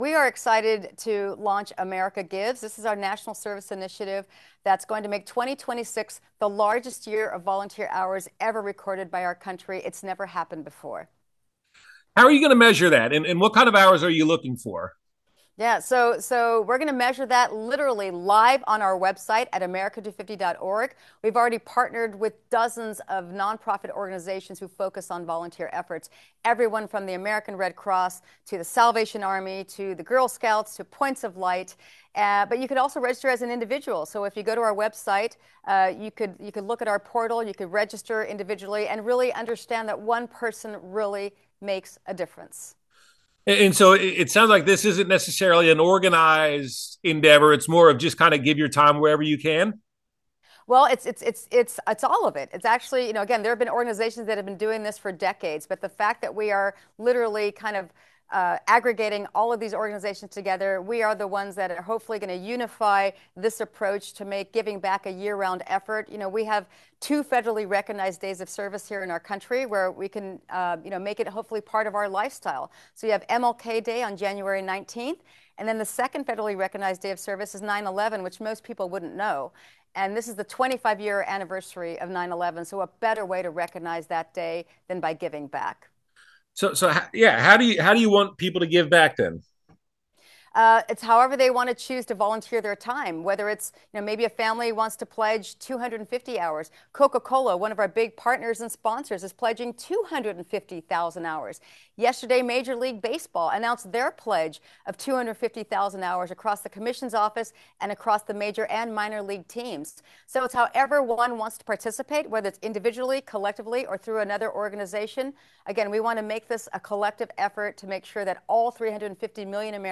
America250's Rosie Rios talks about plans for the big anniversary this July.
America-250-Interview.m4a